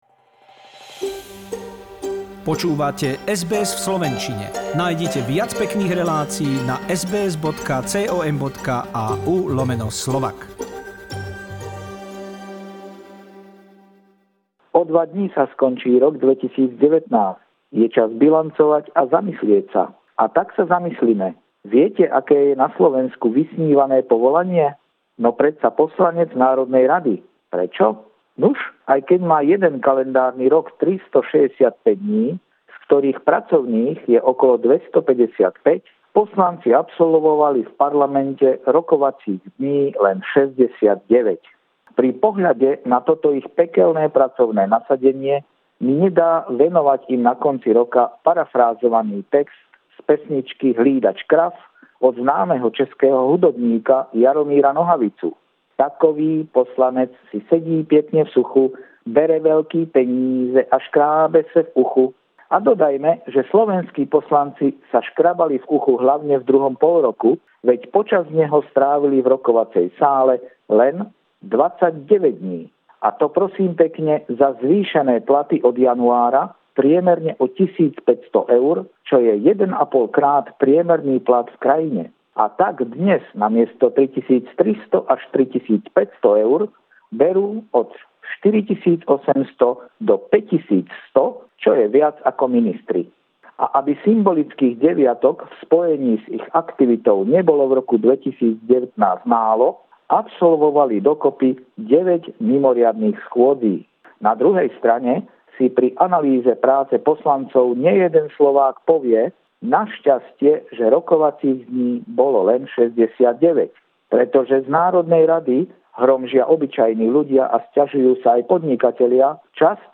Regular stringer report
this time more relaxed.